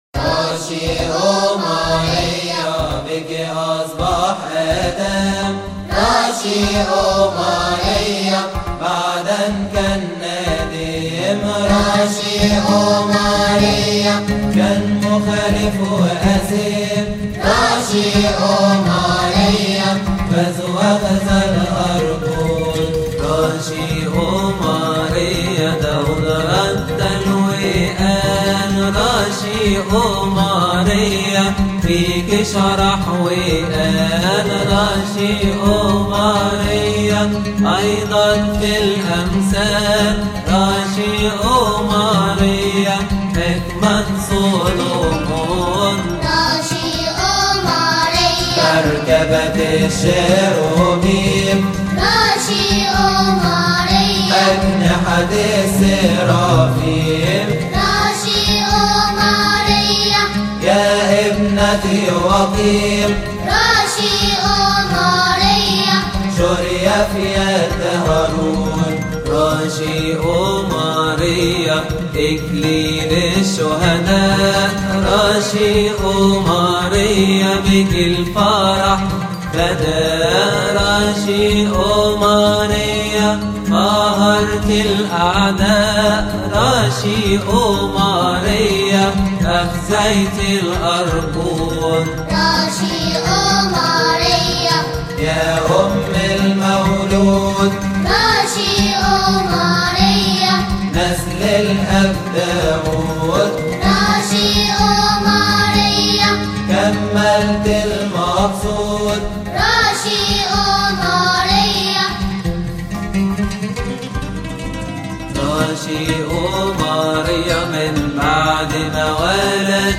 المرتل
يقال في تسبحة نصف الليل بشهر كيهك